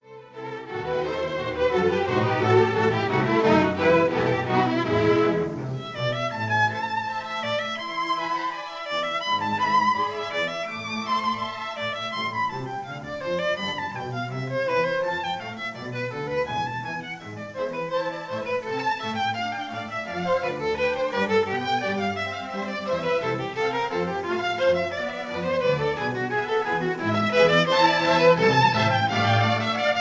conductor
historic 1936 recording